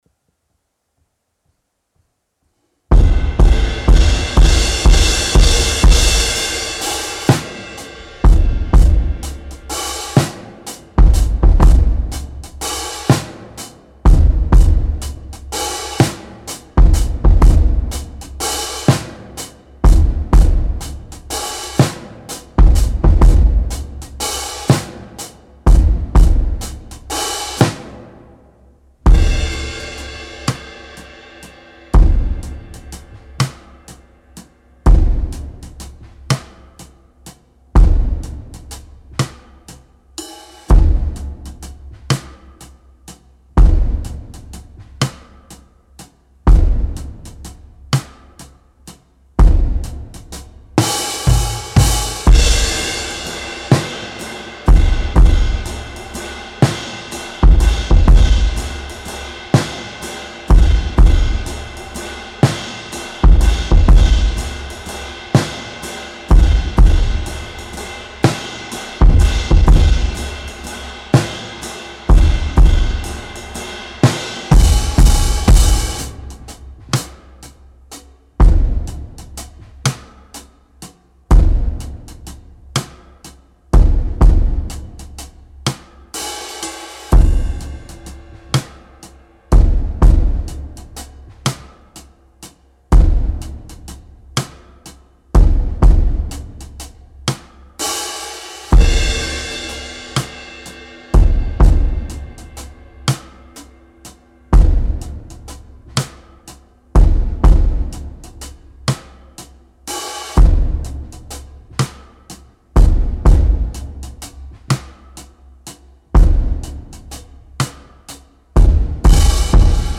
Ballad
Genre:Ballad, Pop
Tempo:62 BPM (6/8)
Kit:Marching kit 30"
Mics:12 channels